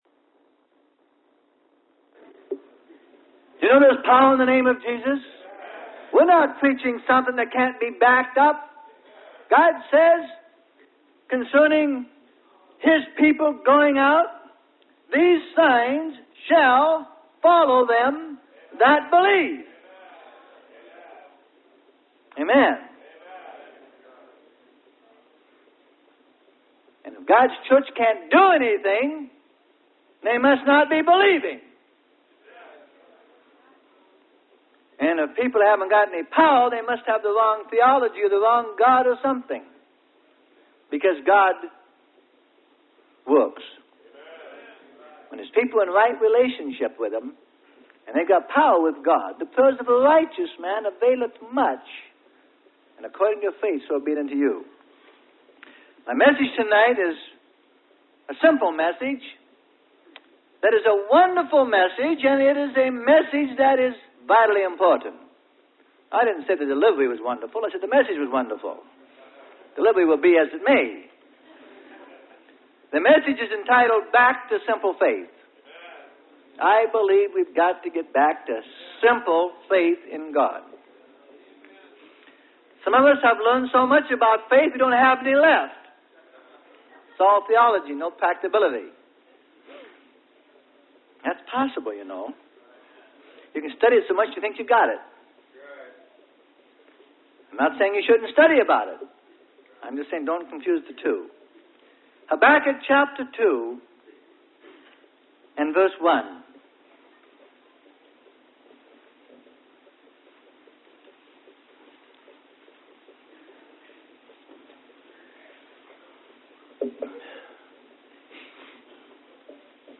Sermon: Back To Simple Faith.